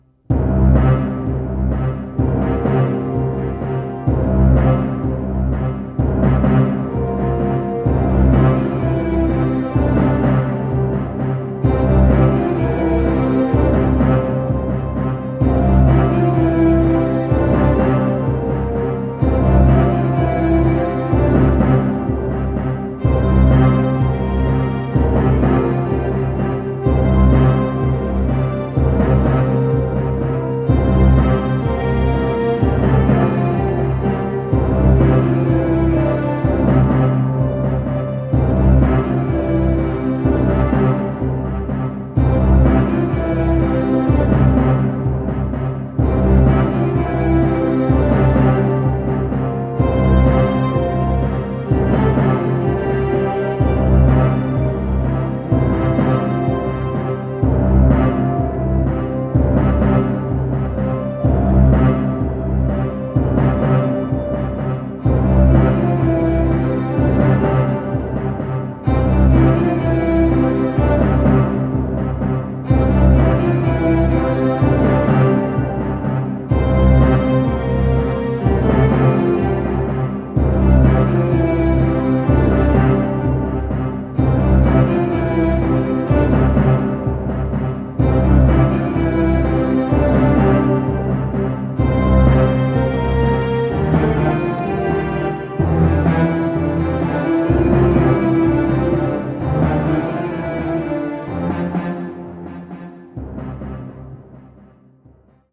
Epic Happy Soundtrack.